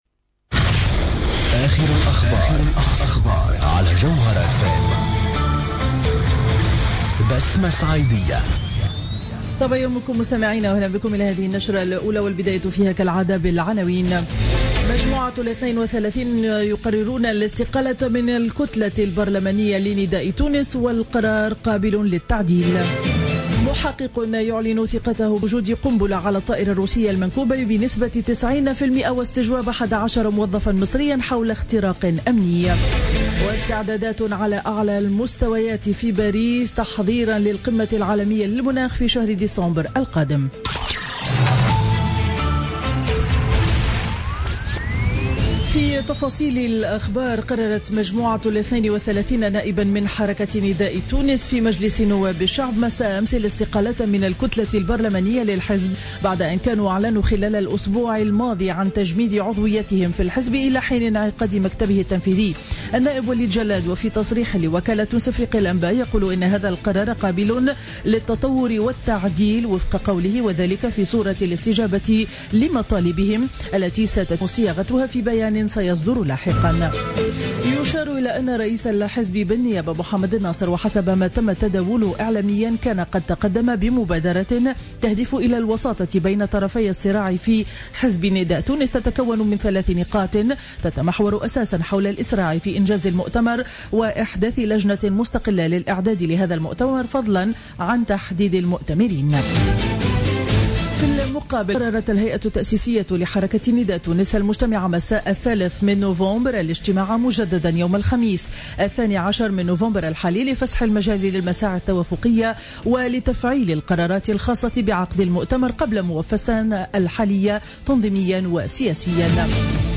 نشرة أخبار السابعة صباحا ليوم الاثنين 09 نوفمبر 2015